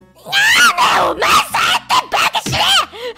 PLAY Minato Aqua swearing
Play, download and share Minato Aqua swearing original sound button!!!!